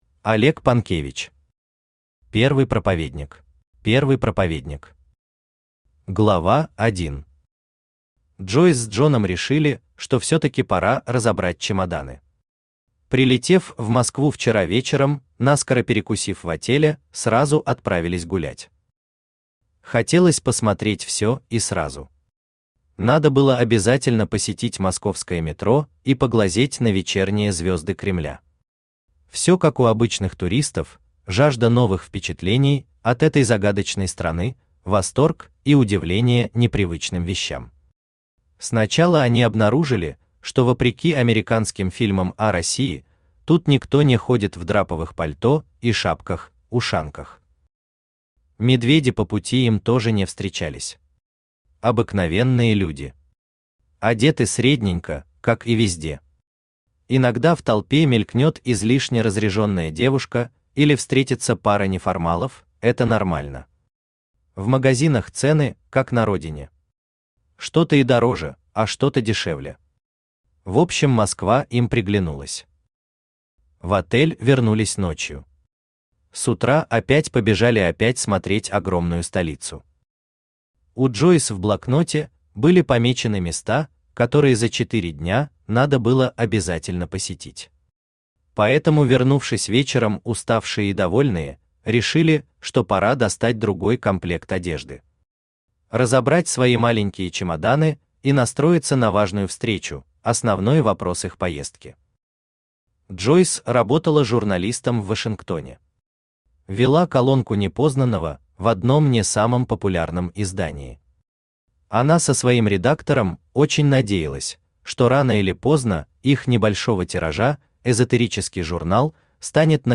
Аудиокнига Первый проповедник | Библиотека аудиокниг
Aудиокнига Первый проповедник Автор Олег Панкевич Читает аудиокнигу Авточтец ЛитРес.